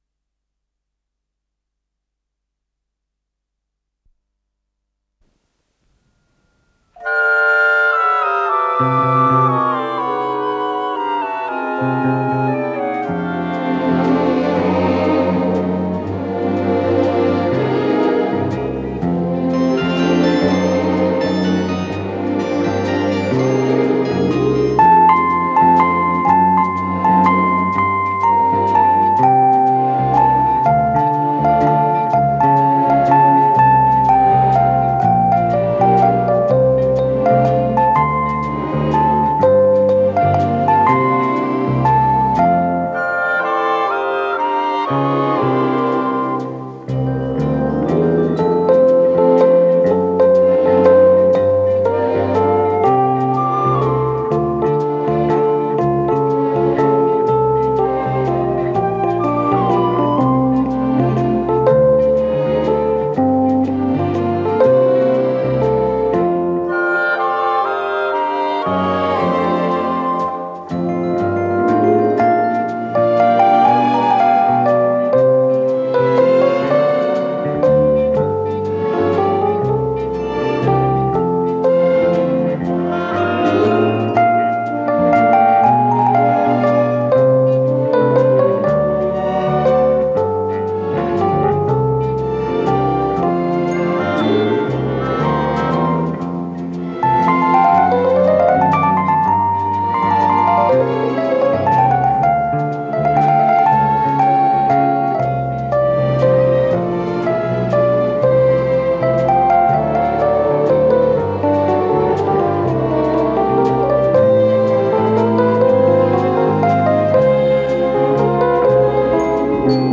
фендер-пиано